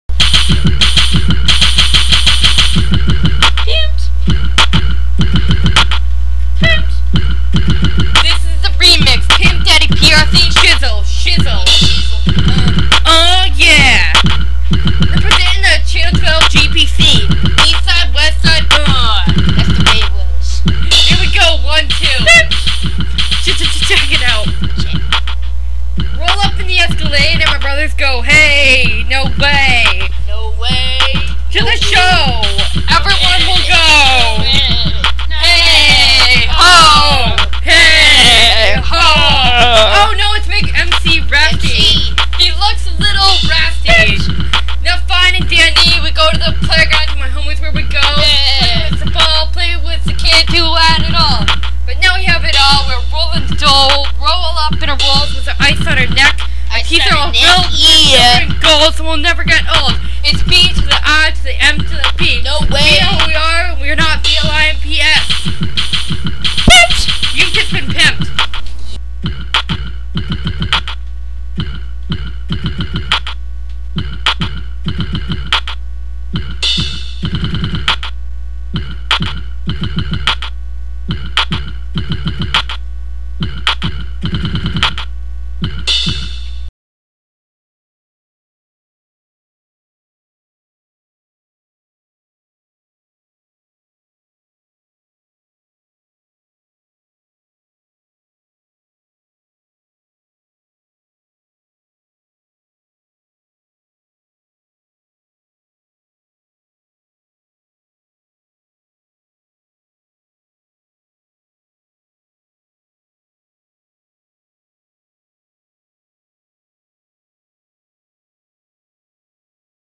I just made a rap song tonight.
prc_rap.wma